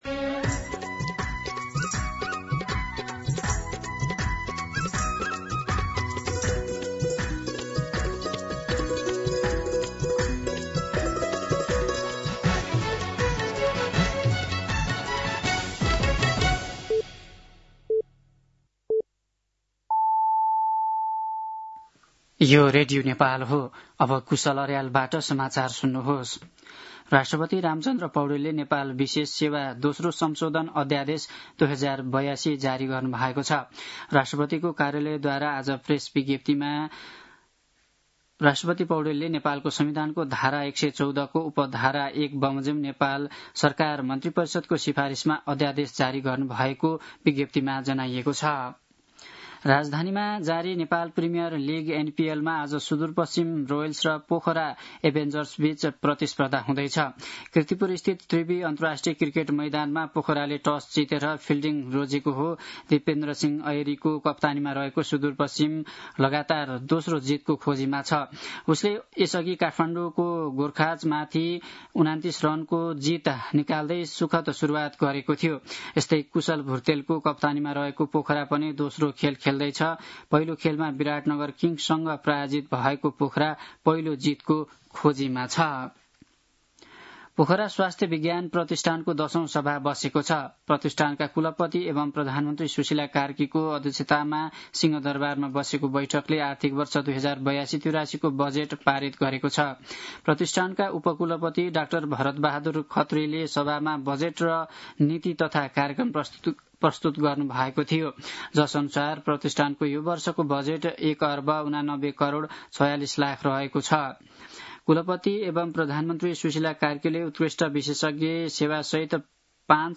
दिउँसो ४ बजेको नेपाली समाचार : ५ मंसिर , २०८२